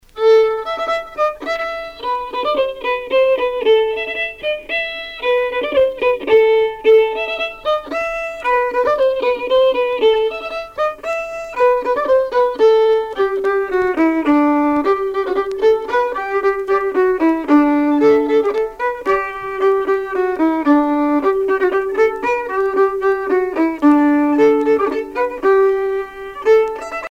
danse : limousine
Pièce musicale éditée